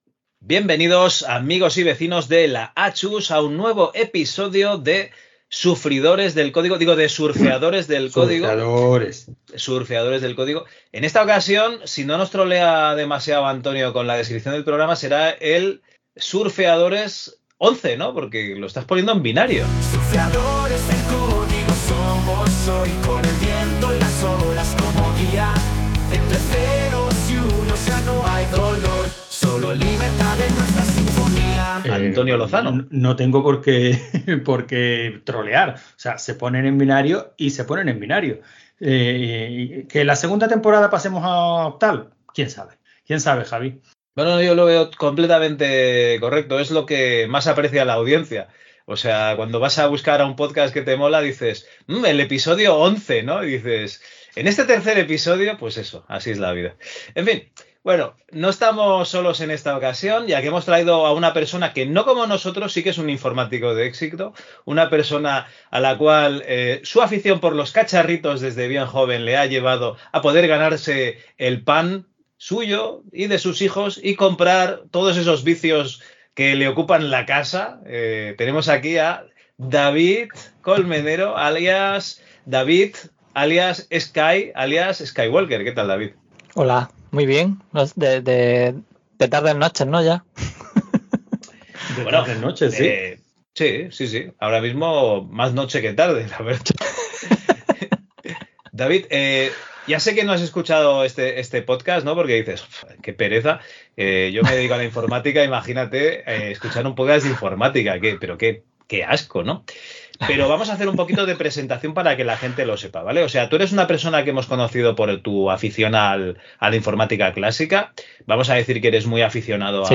La pasión con la que este tío habla de lo que le apasiona anima a cualquiera a echarle ganas.